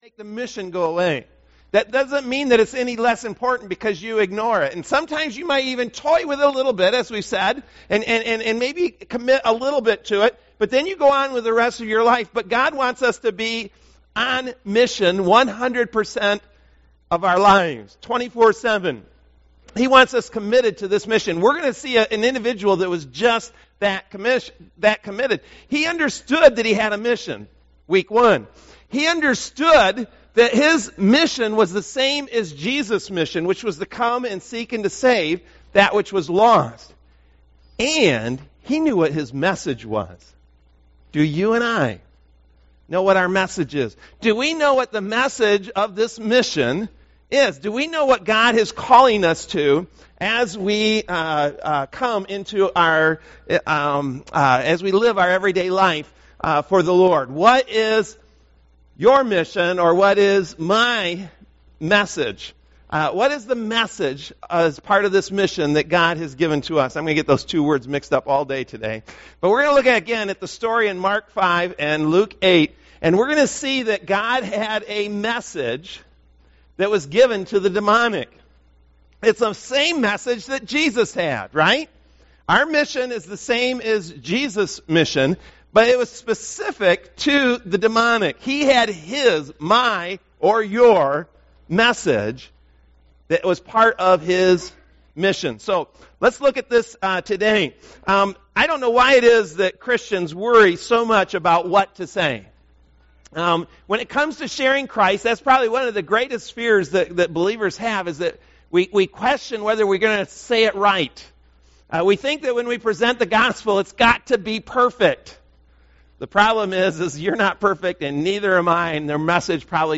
MP3 SUBSCRIBE on iTunes(Podcast) Notes Discussion Sermons in this Series April 22, 2018 Loading Discusson...